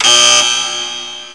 BUZZER3.WAV